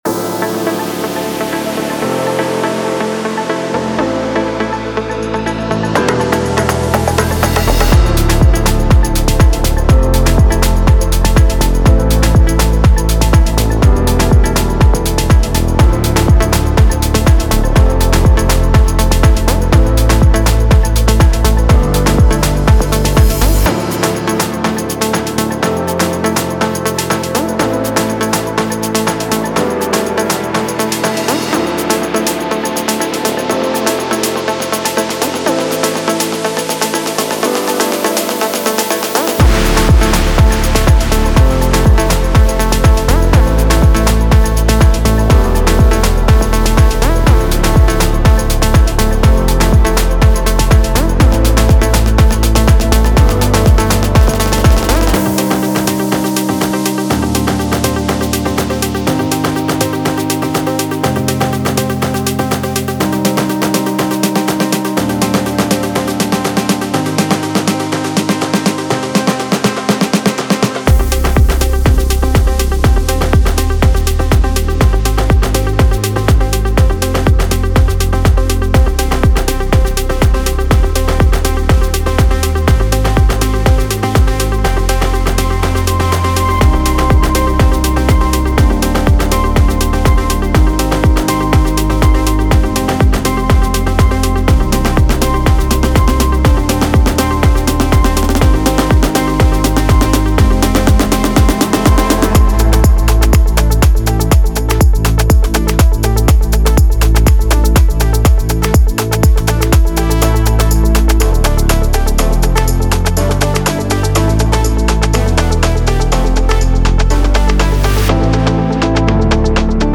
Genre:Afro House
デモサウンドはコチラ↓